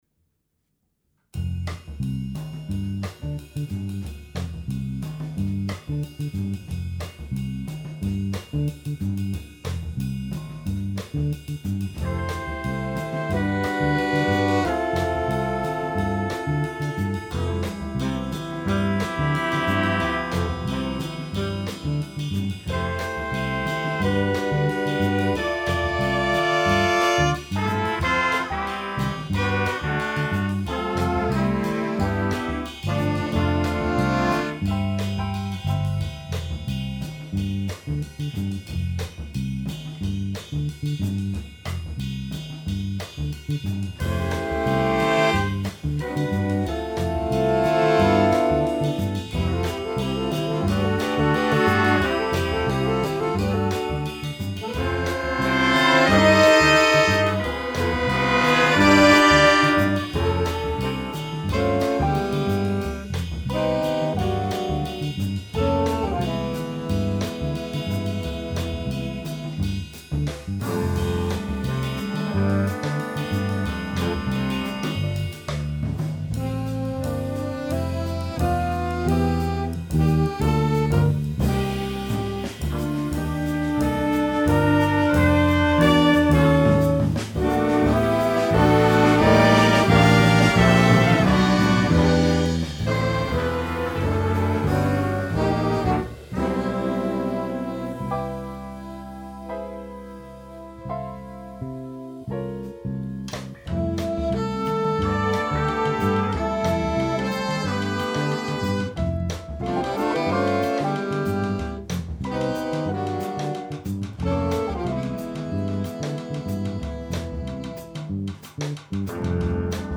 FOR BIG BAND
Category: Big Band